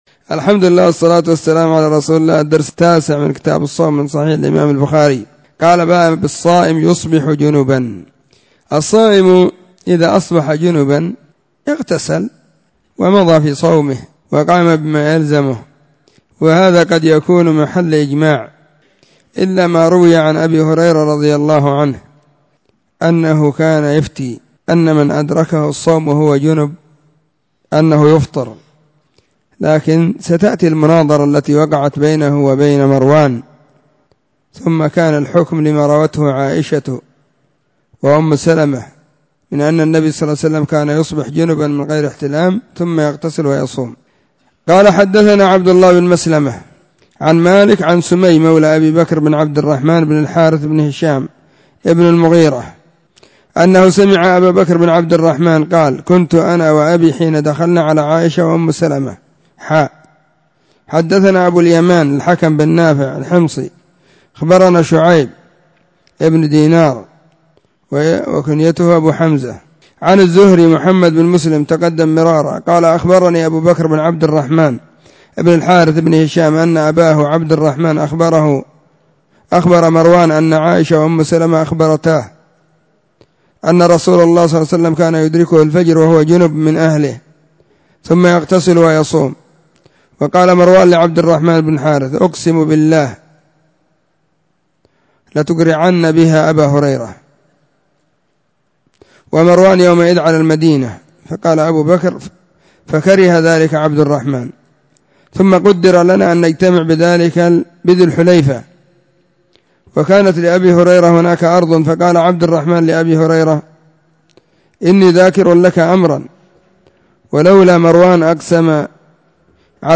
🕐 [بين مغرب وعشاء – الدرس الثاني]